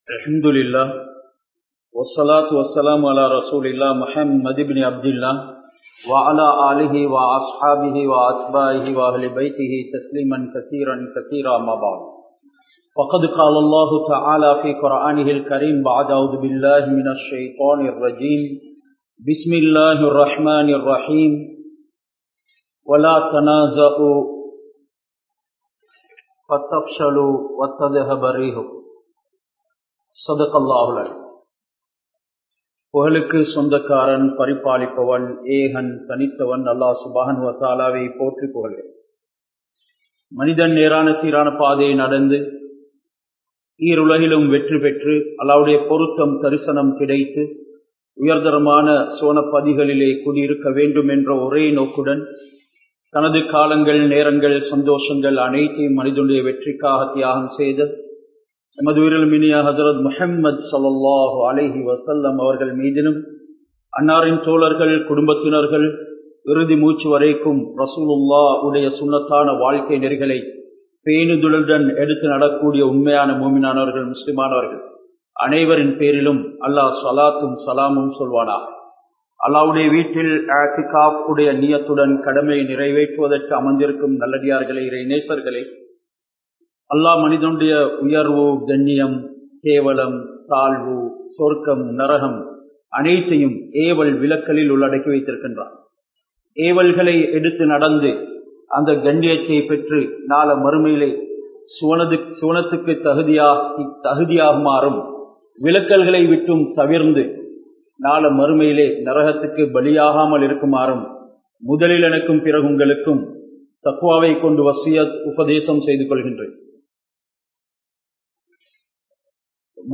Zulaiha,Nabi Yoosuf(Alai)Avarhalin Manaiviya? (சுலைஹா, நபி யூஸூப்(அலை)அவர்களின் மனைவியா?) | Audio Bayans | All Ceylon Muslim Youth Community | Addalaichenai